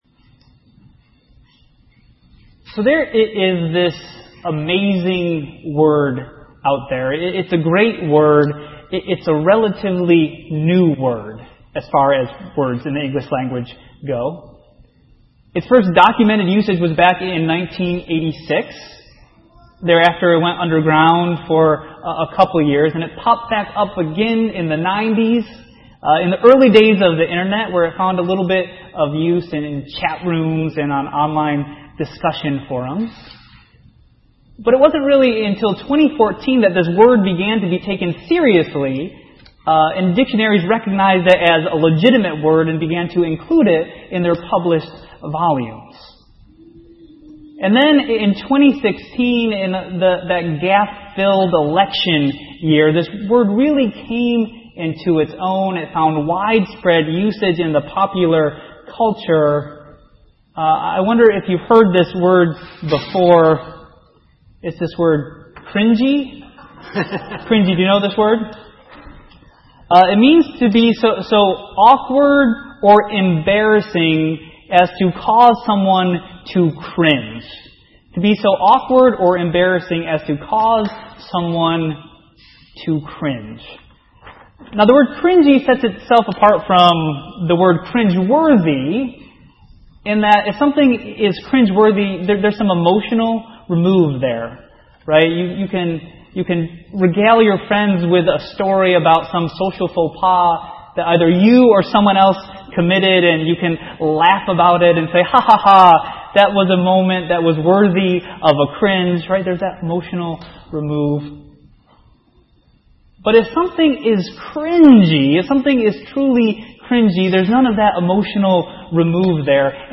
A message from the series "Narrative Lectionary."